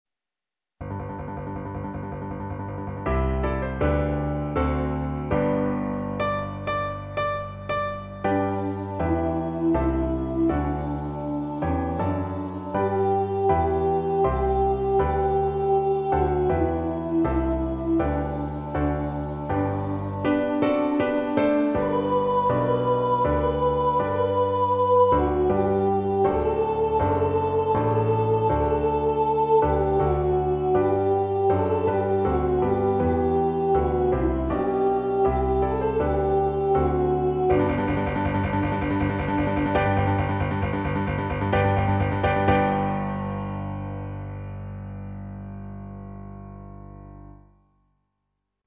If you would like to practice up on a vocal part, here are some part recordings which may assist you.